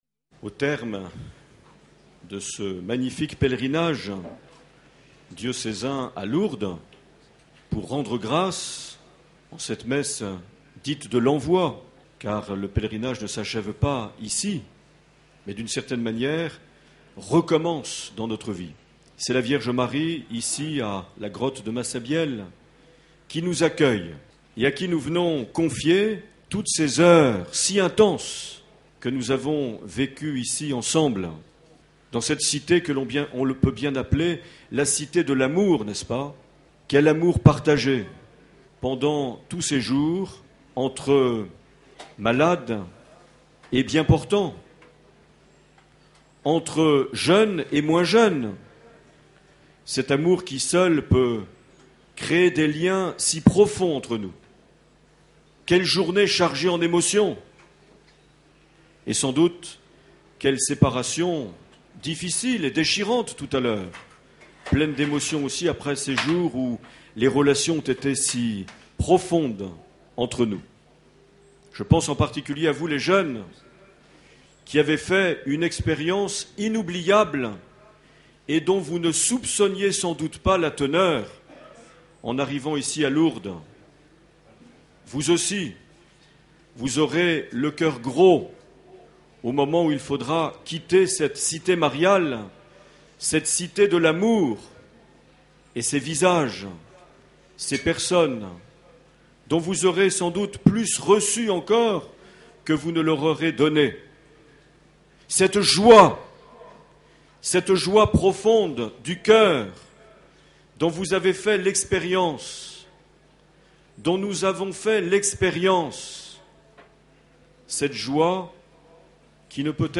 21 septembre 2009 - Lourdes Grotte Massabielle - Messe de clôture du Pèlerinage Diocésain